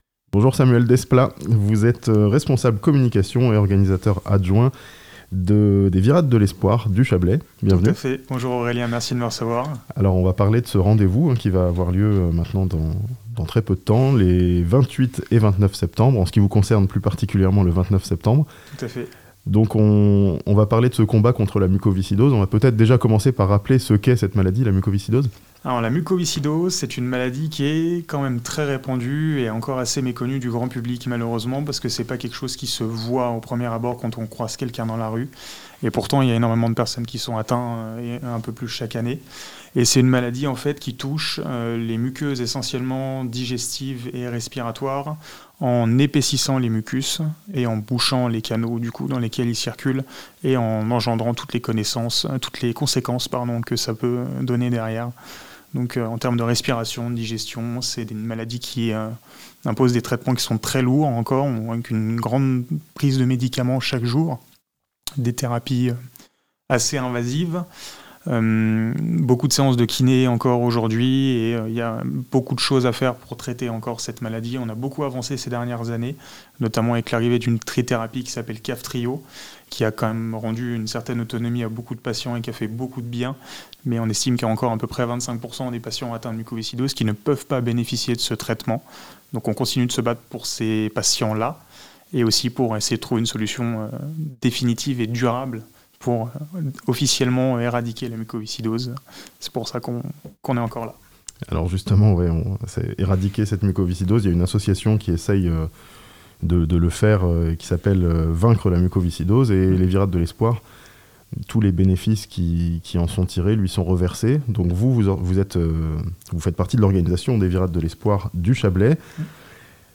Les Virades de l'Espoir du Chablais auront lieu à Perrignier le 29 septembre (interview)